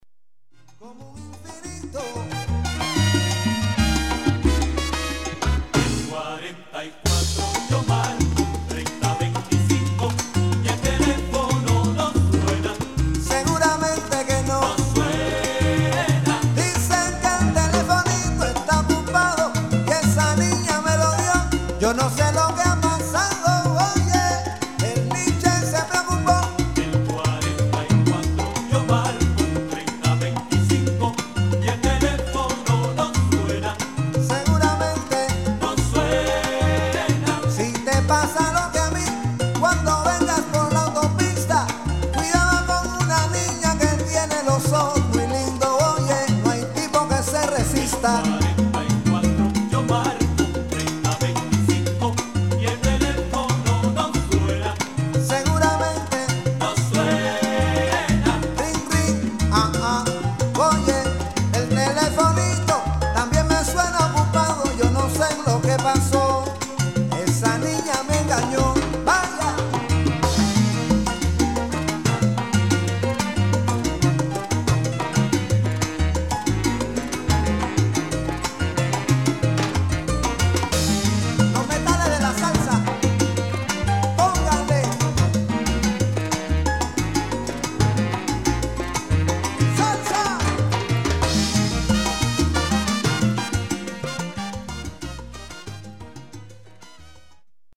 90S Cuban Salsa